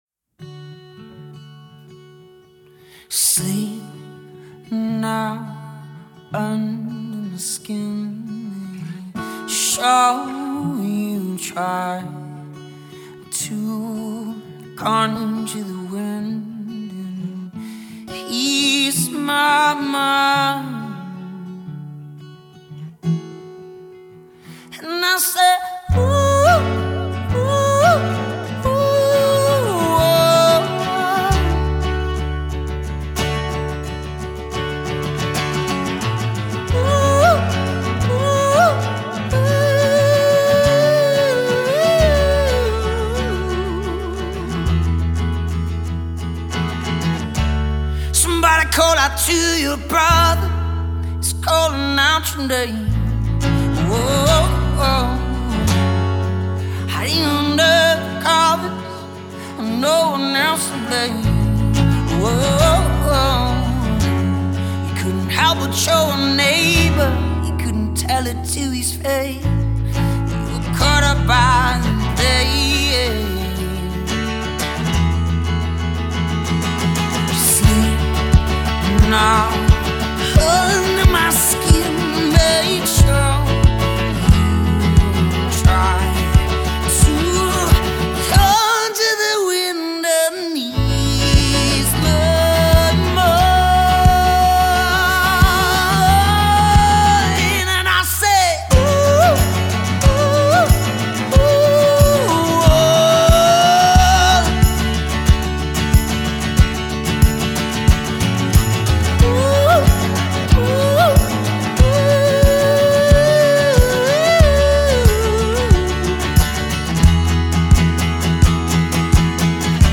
Vocals | Guitar | Looping | Live Singing with DJ